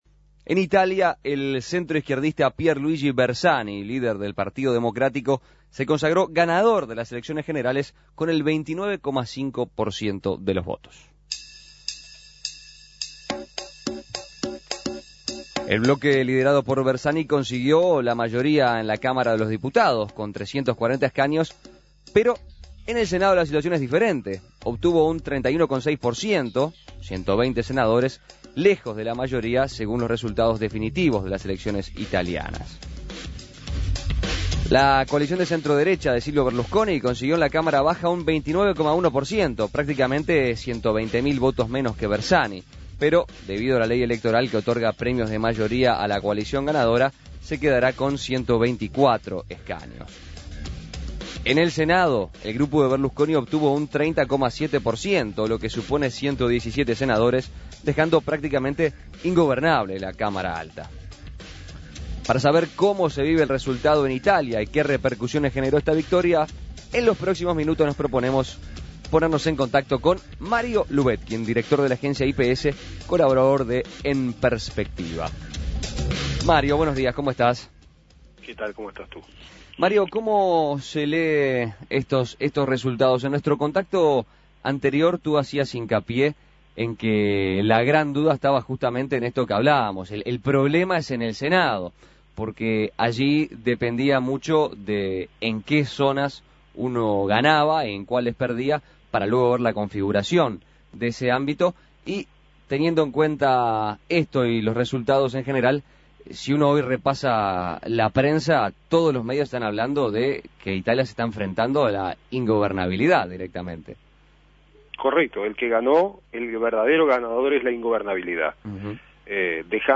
Contacto con Mario Lubetkin, colaborador de En Perspectiva en Roma